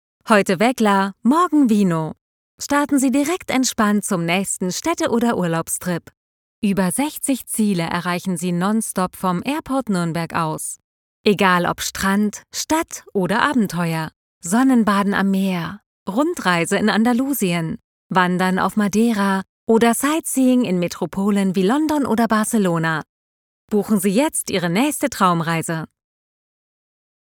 Friendly, Warm, Corporate
Commercial